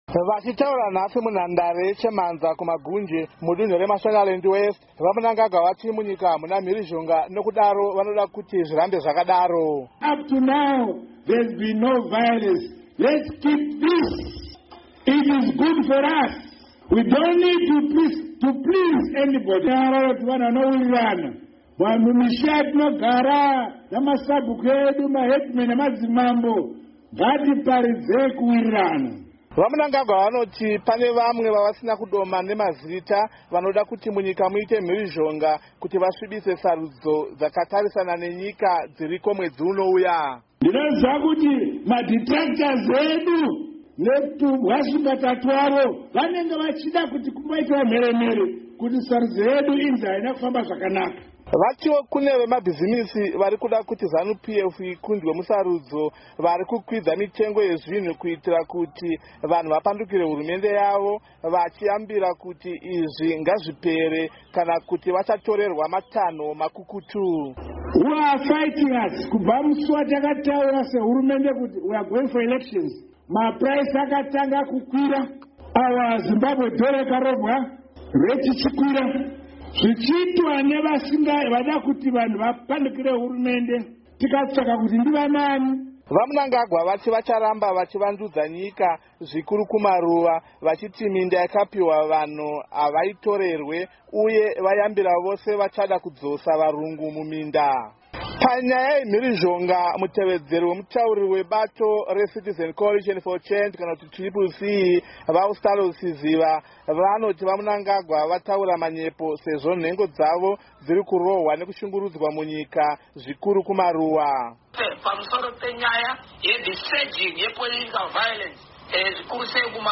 Vachitaura nhasi munhandare yeChemhanza kuMagunje, mudunhu reMashonaland West, VaMnangagwa vati munyika hamuna mhirizhonga nekudaro vanoda kuti zvirambe zvakadaro.